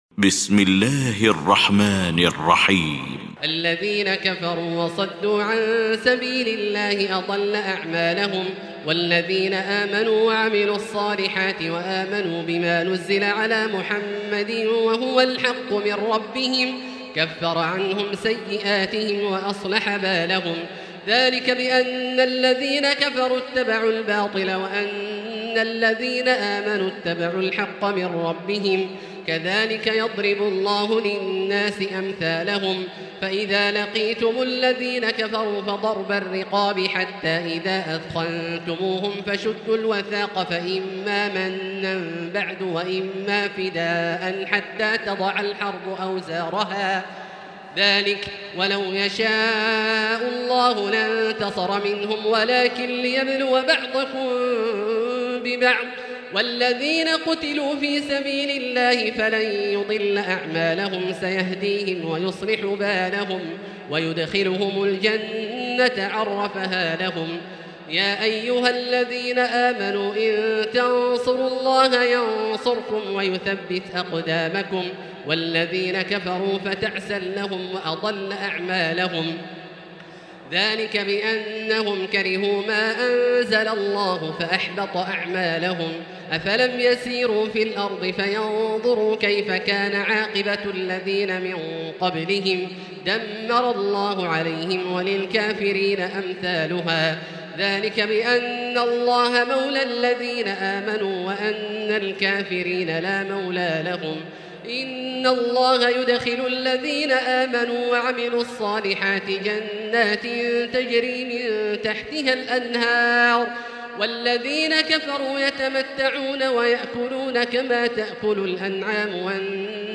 المكان: المسجد الحرام الشيخ: فضيلة الشيخ عبدالله الجهني فضيلة الشيخ عبدالله الجهني محمد